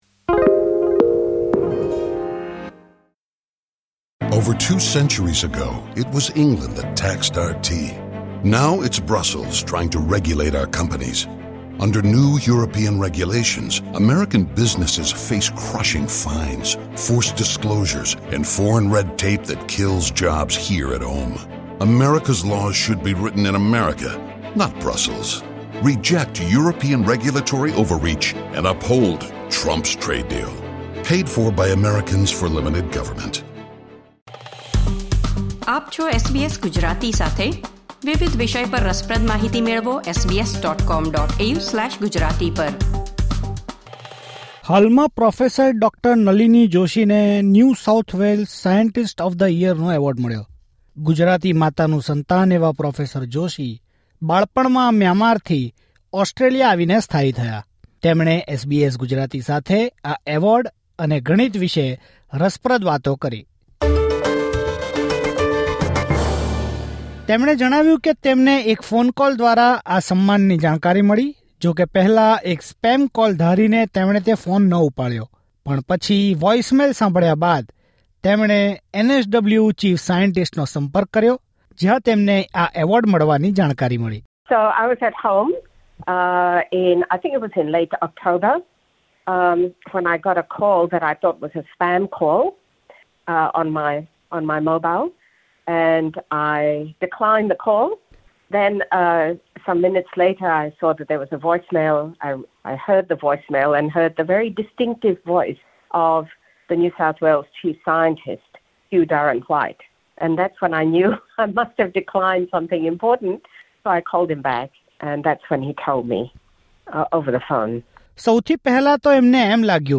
હાલમાં પ્રોફેસર ડો નલિની જોશીને NSW સાયન્ટિસ્ટ ઓફ ધ યરનો એવોર્ડ મળ્યો. ગણિતજ્ઞ હોવા ઉપરાંત તેઓ STEM ક્ષેત્રમાં મહિલાઓની ભાગીદારી વધારવામાં પણ ખૂબ અગ્રસર છે. SBS ગુજરાતી સાથે તેમણે આ બધા અંગે વિગતવાર વાત કરી.